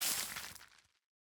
Minecraft Version Minecraft Version snapshot Latest Release | Latest Snapshot snapshot / assets / minecraft / sounds / item / bonemeal / bonemeal3.ogg Compare With Compare With Latest Release | Latest Snapshot
bonemeal3.ogg